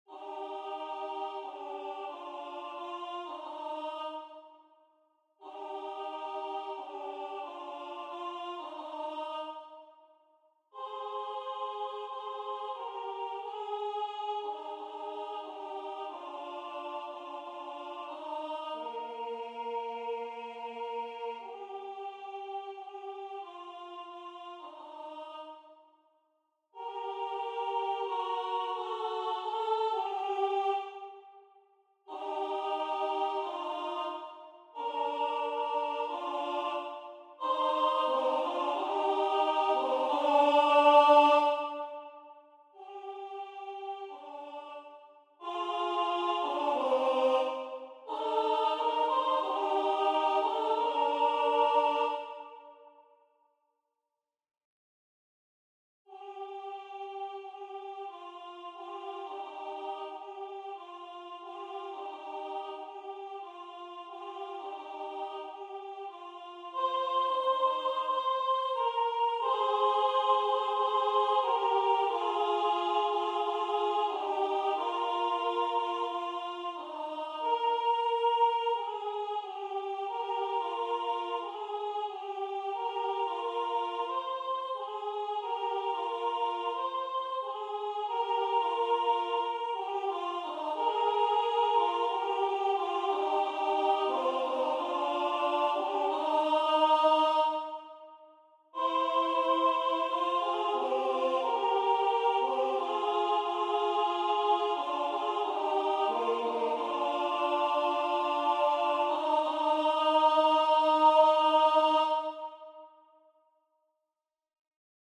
MP3 rendu voix synth.